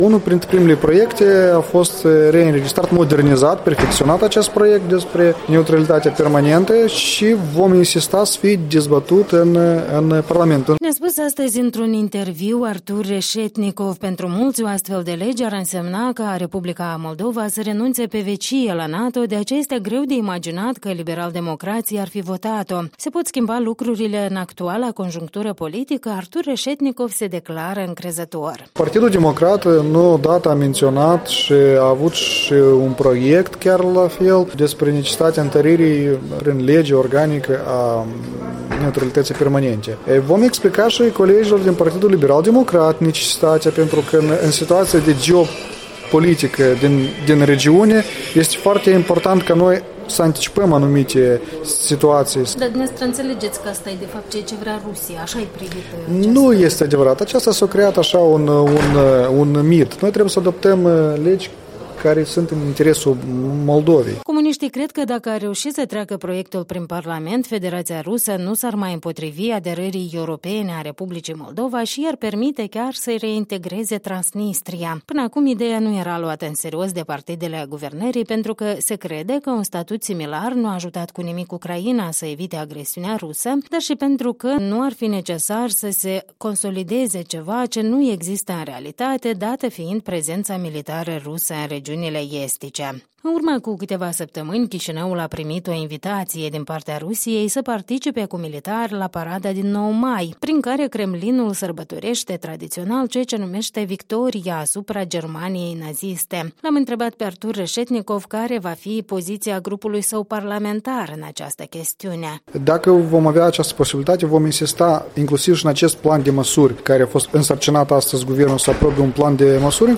în dialog cu deputatul comunist Artur Reşetnicov